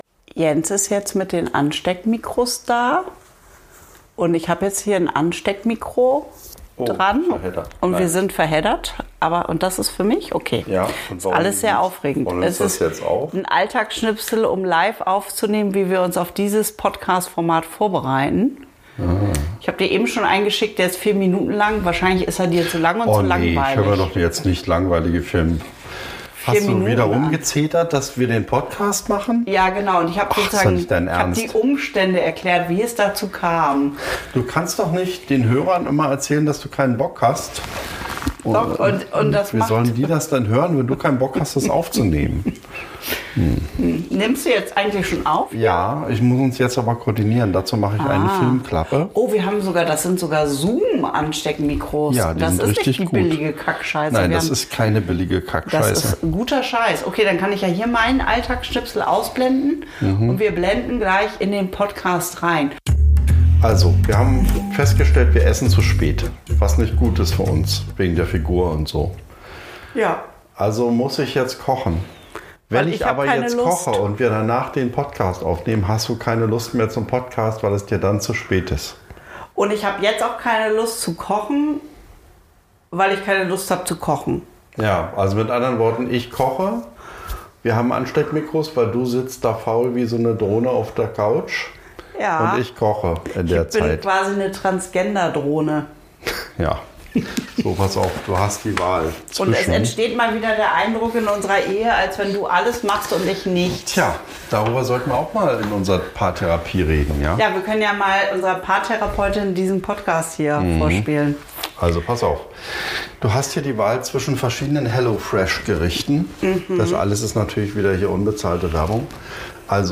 Beschreibung vor 1 Monat Bauch an Bauch Vom Kochen mit Ansteckmikros, würdevollen Toiletten & Bauamts-Brettspielen Diese Folge beginnt mit einem kleinen Experiment: Ansteckmikros.